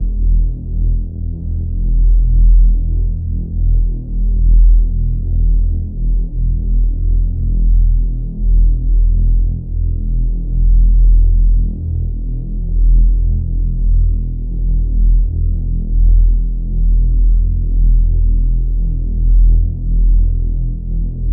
pbs - metro & 40 [ Sub ].wav